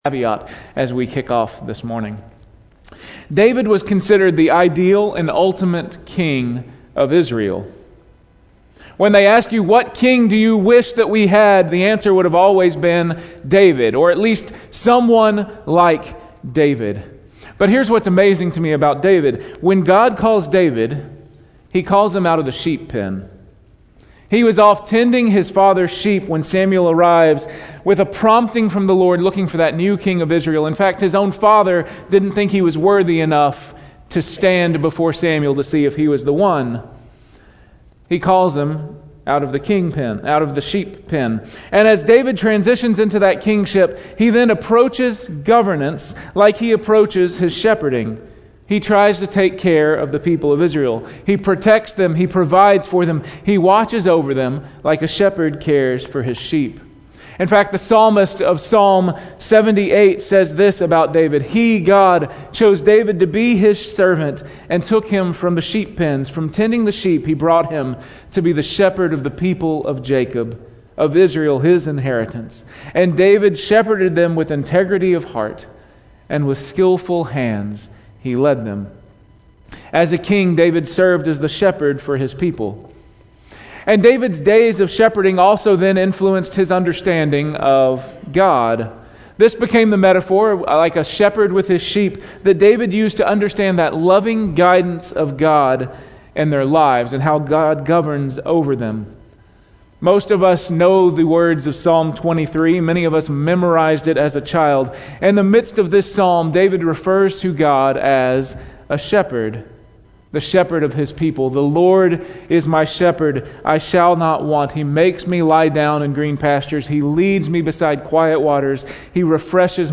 Weekly Sermon Audio